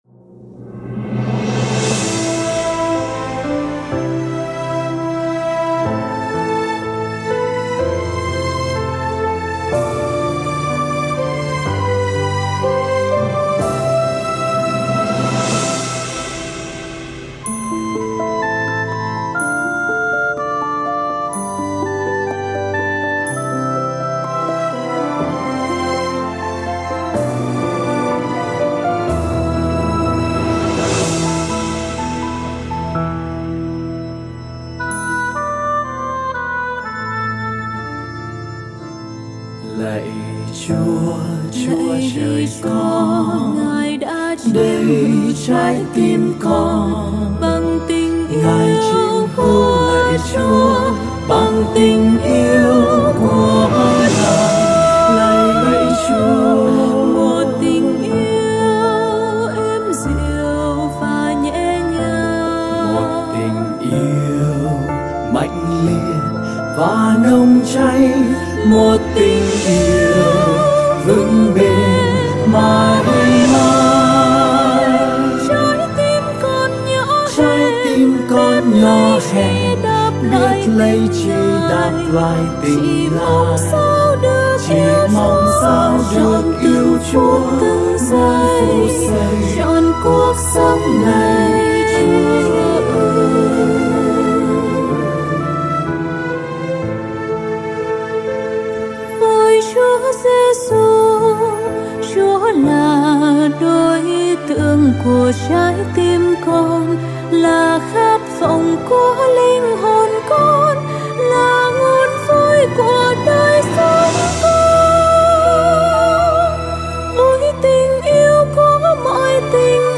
Thánh Ca Dâng Hiến